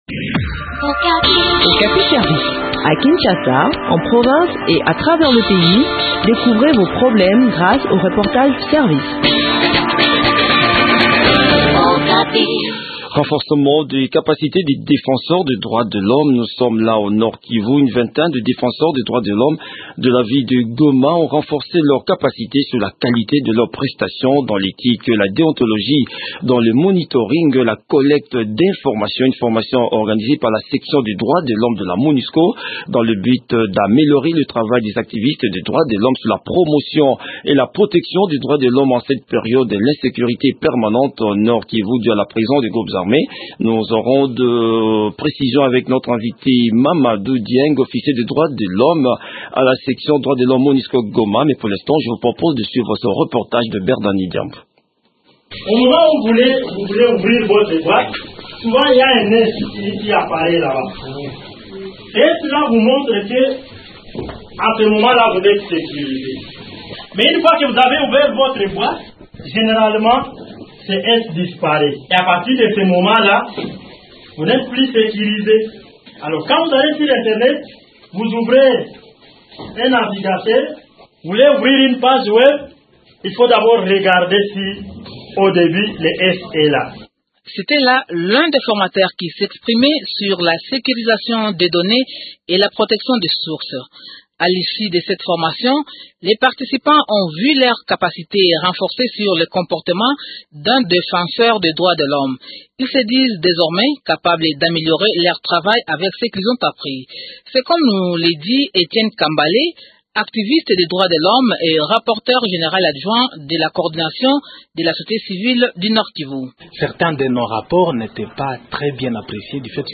Des précisions sur cette formation dans cet entretien